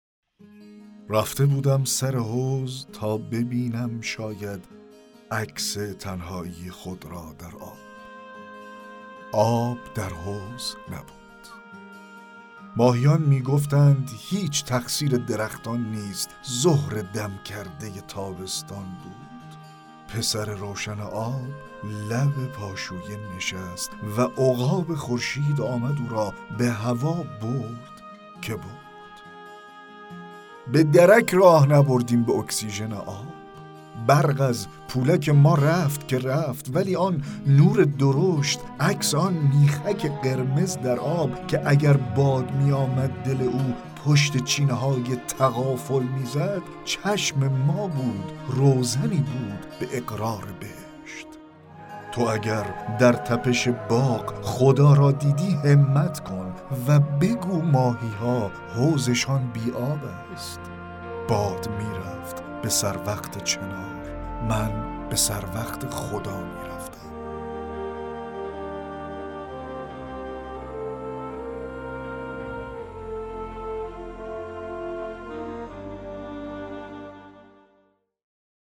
با صدای بسیار زیبا و دلنشین